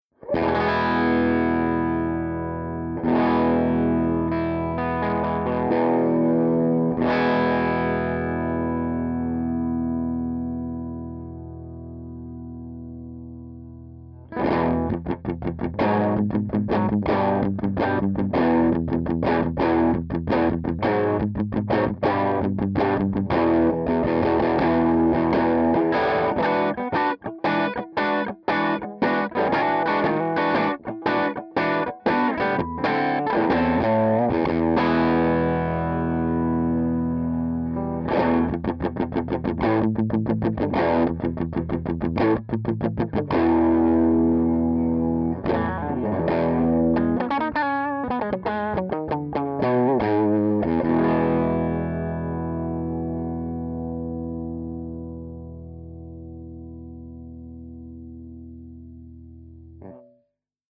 005_AC30_TOPBOOST_HB.mp3